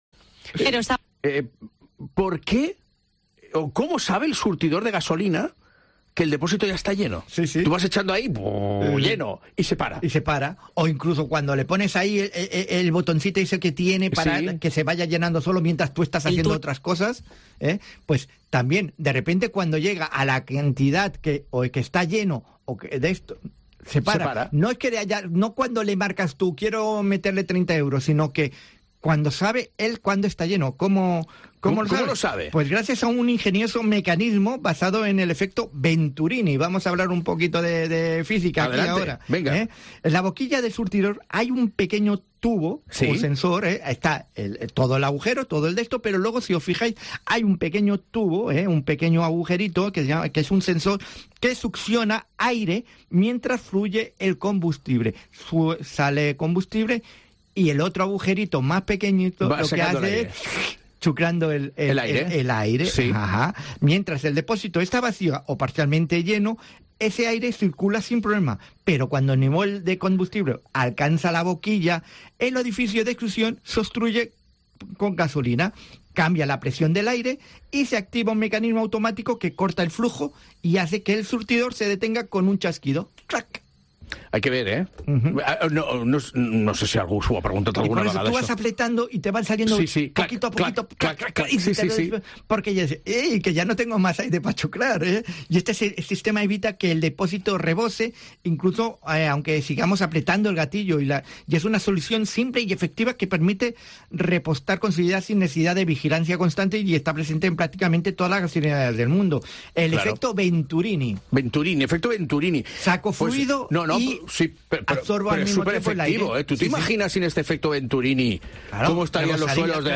Divulgador